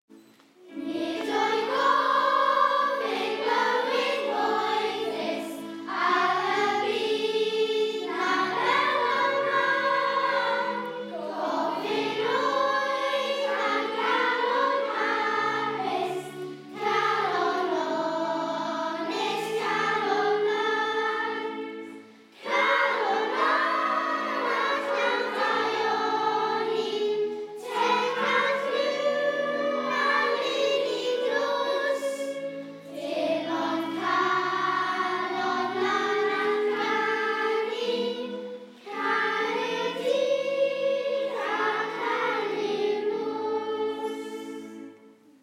Dunraven sing Calon Lan